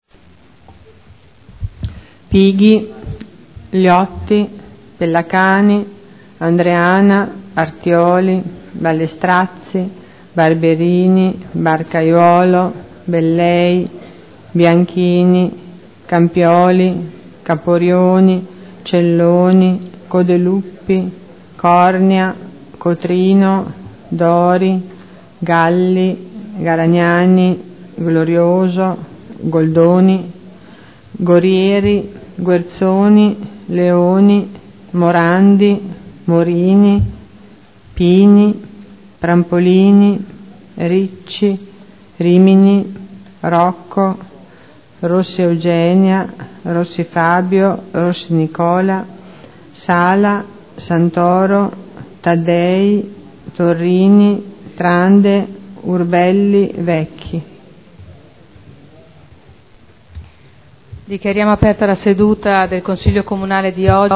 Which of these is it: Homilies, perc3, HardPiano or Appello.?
Appello.